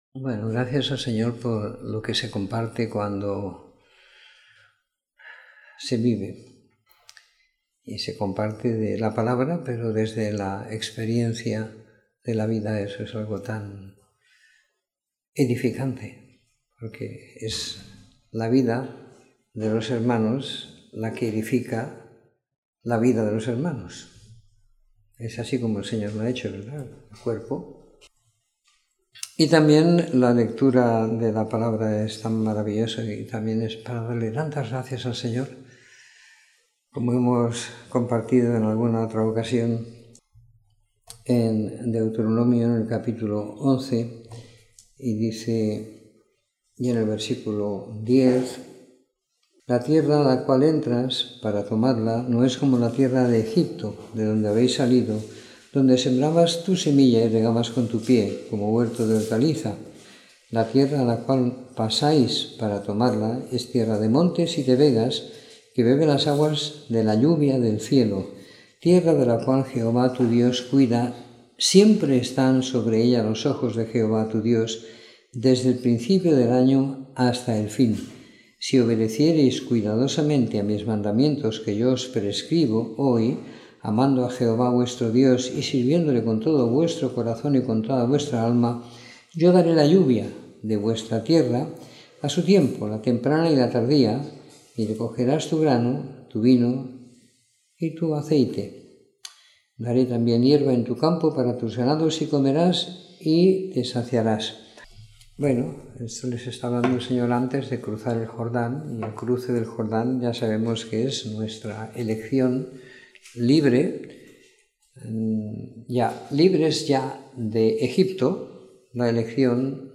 Comentario en Romanos 1-5 - 22 de Junio de 2018